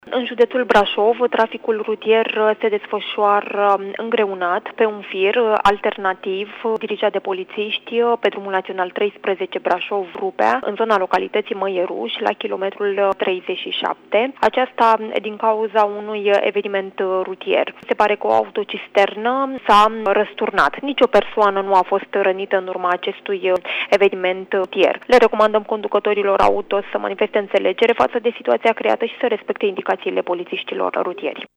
Evenimentul rutier nu s-a soldat cu victime, iar polițiștii dirijează traficul, a explicat inspectorul de poliție